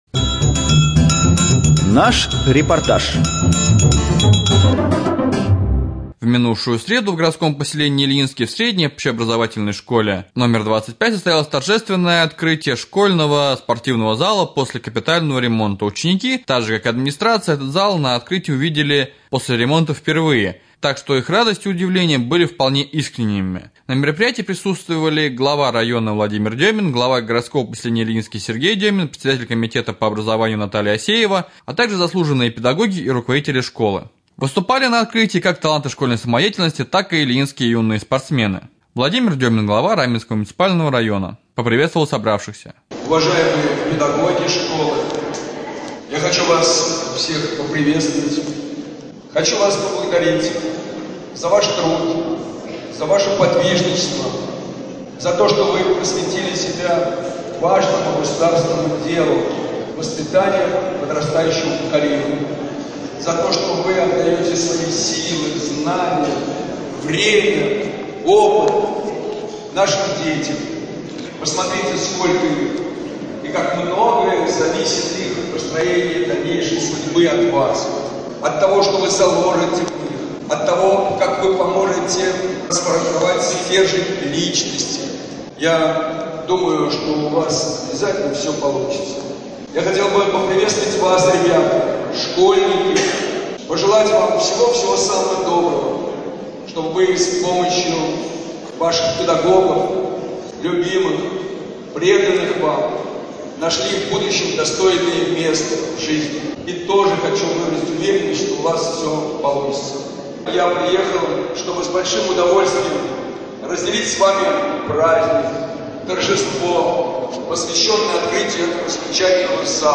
4.Рубрика «Специальный репортаж». В Ильинской школе №25 после капитального ремонта открылся спортивный зал.